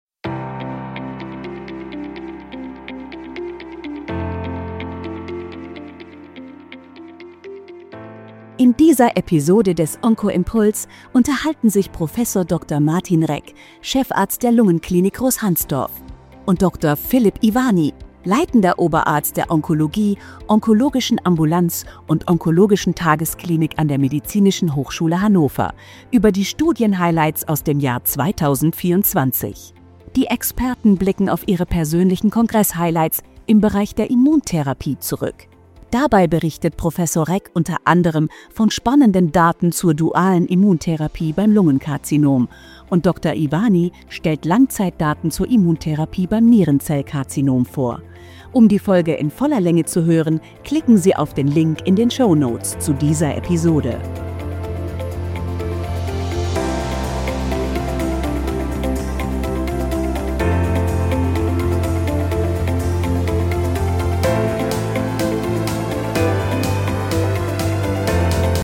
Dialog zum Jahresrückblick 2024